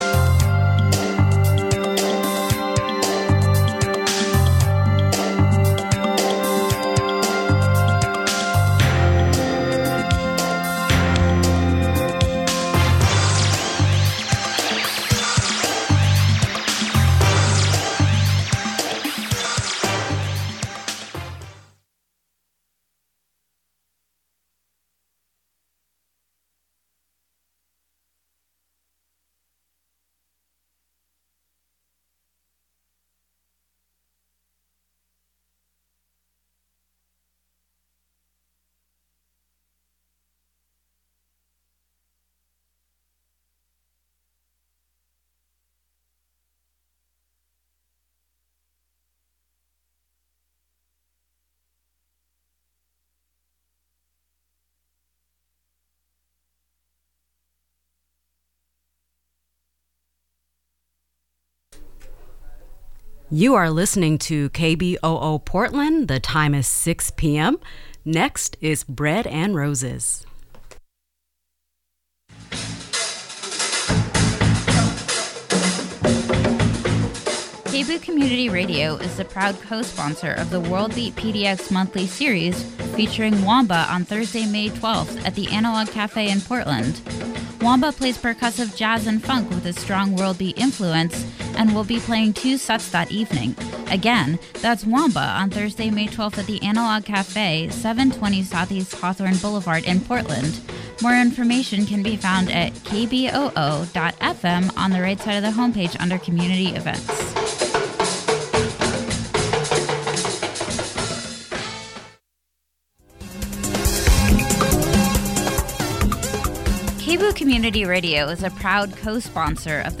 a conversation about collaborations, collectives and cooperatives